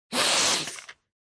Descarga de Sonidos mp3 Gratis: aspirar.